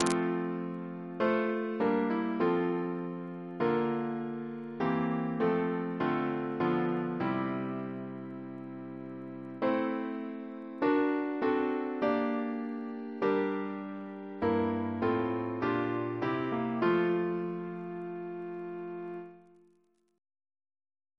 Double chant in F Composer: Edward John Hopkins (1818-1901), Organist of the Temple Church Reference psalters: ACB: 296; CWP: 82; PP/SNCB: 13; RSCM: 73